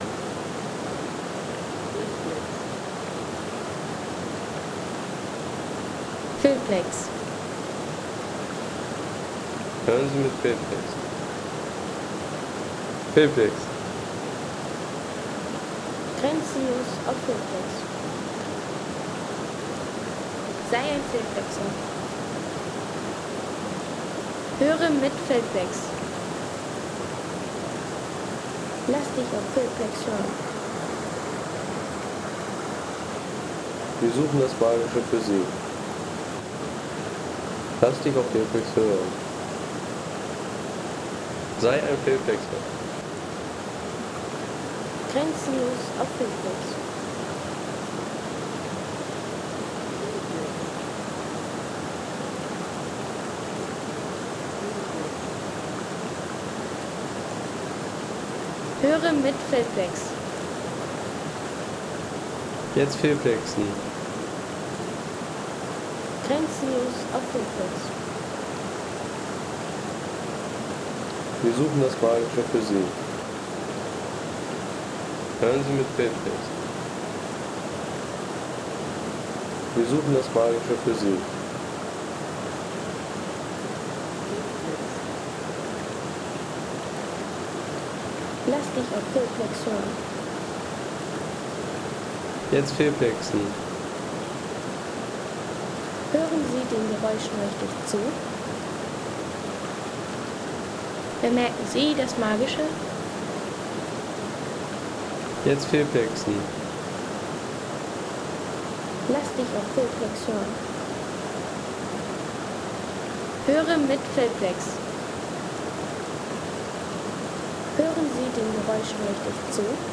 Wasserfall Cascata del Toce, Formazza Alpen | Feelplex
Cascata del Toce in den Alpen (Formazza): kraftvolles, faszinierendes Wasserrauschen, direkt am Fuß aufgenommen.
Direkt am Fuß der Cascata del Toce in Formazza aufgenommen: intensives Wasserrauschen und echte Alpen-Atmosphäre für Film, Podcast und Klangpostkarten.